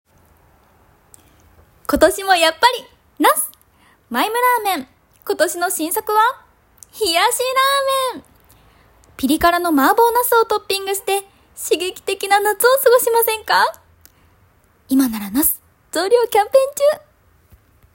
ボイスサンプル
CM１